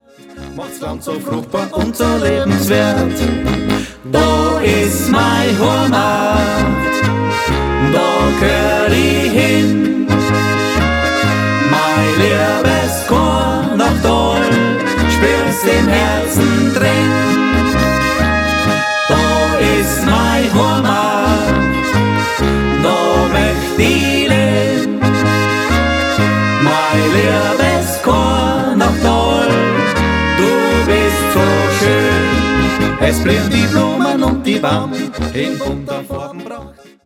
Typische weststeirische Volksmusik!